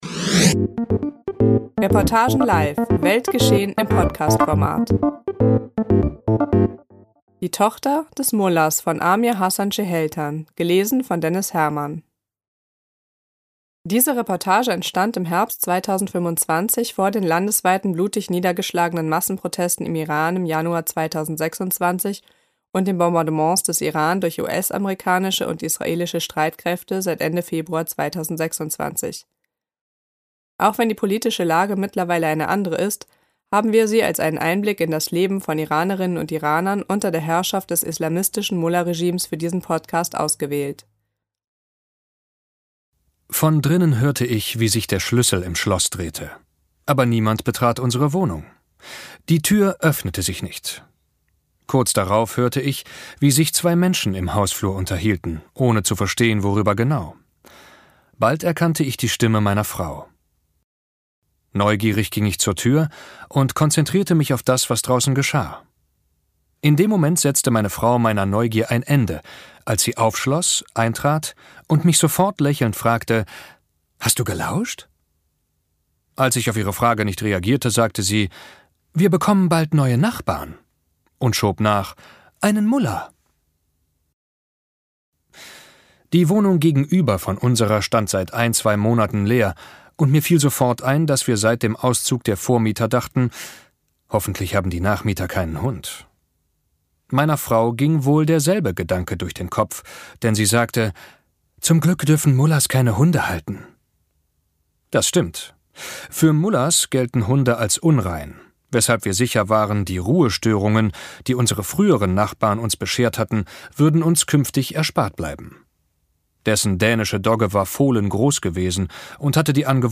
Cheheltans Reportage, geschrieben vor Ausbruch des Krieges, ist ein literarisches Dokument der Stunde: Sie zeigt, was für die Menschen im Iran heute mehr denn je auf dem Spiel steht.